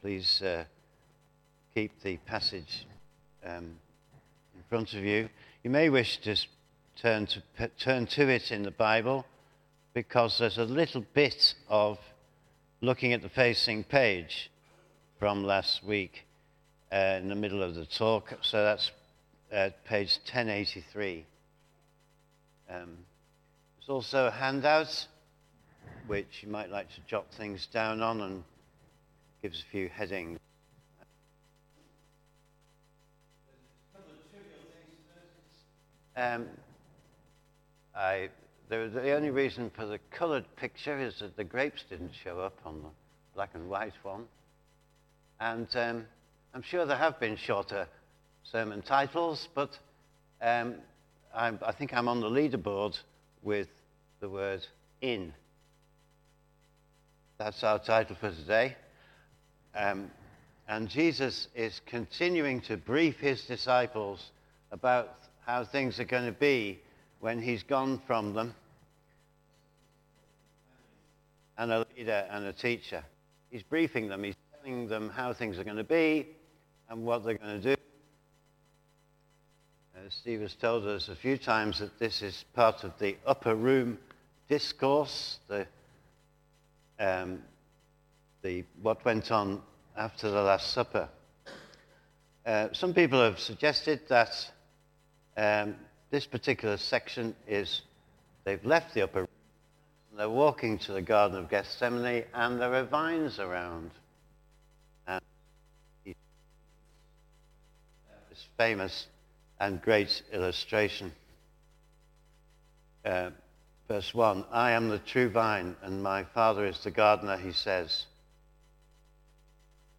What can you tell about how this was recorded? Media Library The Sunday Sermons are generally recorded each week at St Mark's Community Church.